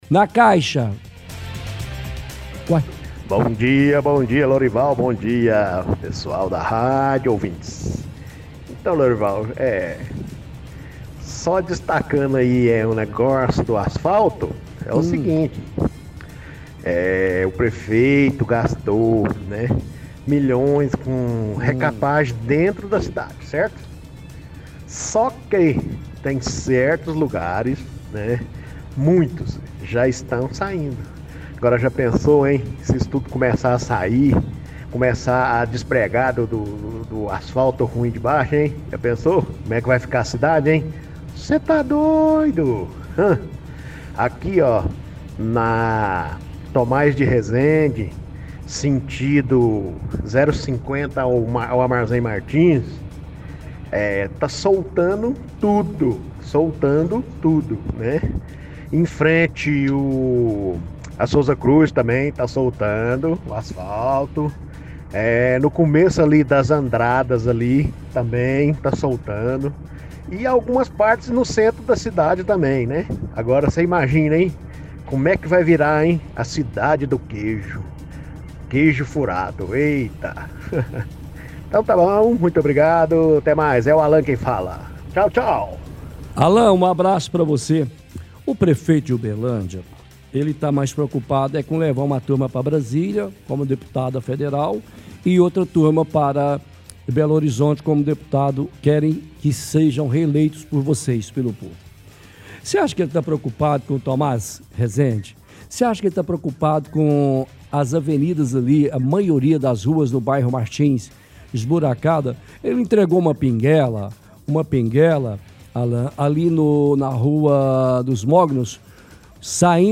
– Ouvinte reclama que o prefeito gastou muito com recapeamento e que o asfalto já está saindo.